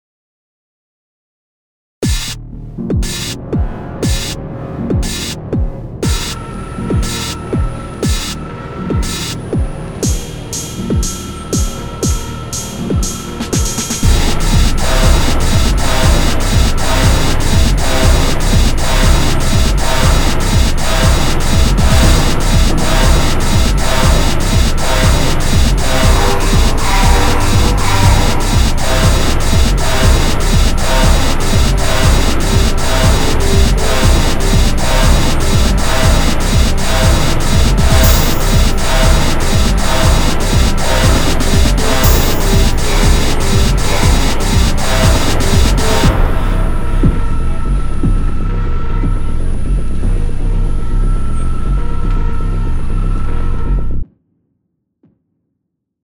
BGM
EDMショート